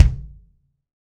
Q BD ATM mf mx1.WAV